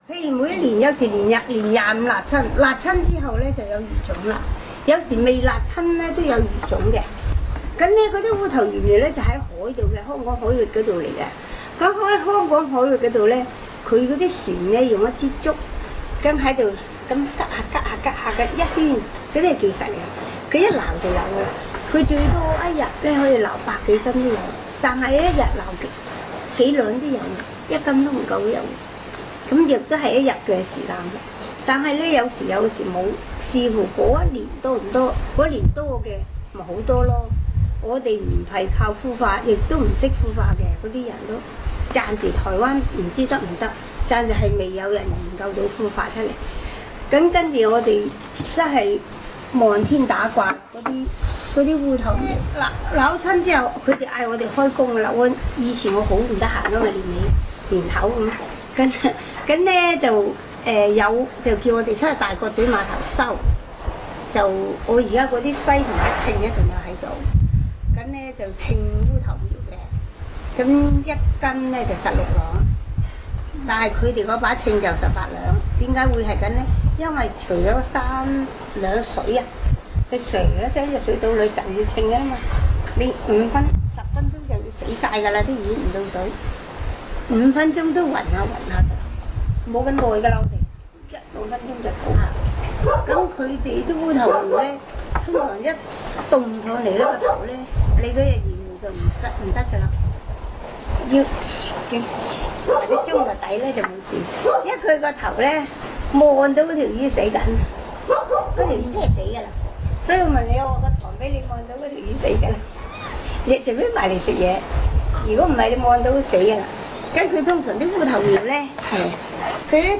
口述歷史錄音片段